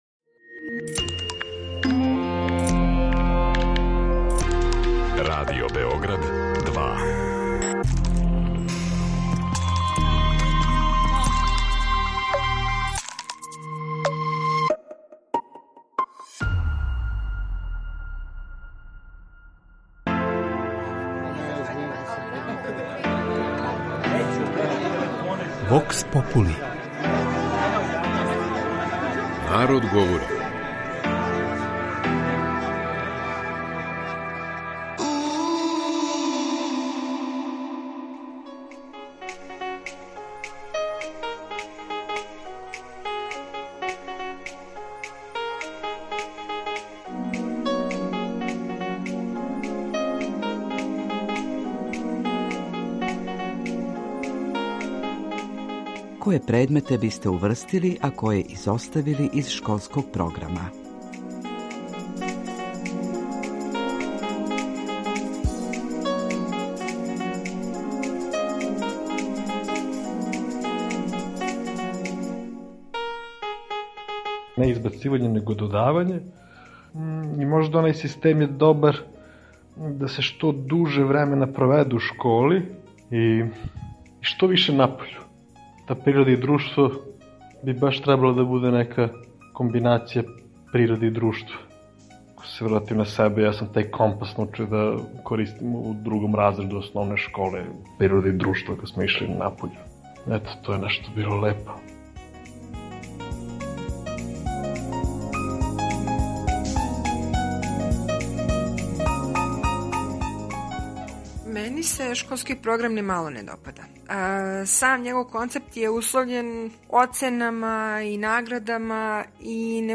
У данашњој емисији наши суграђани коментарисали су концепт школског система у Србији и предложили неке измене у школском плану и програму.
Вокс попули